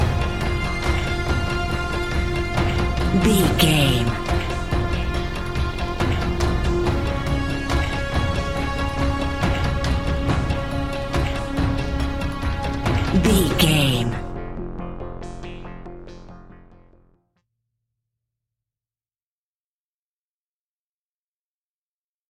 In-crescendo
Thriller
Aeolian/Minor
ominous
eerie
horror music
Horror Pads
horror piano
Horror Synths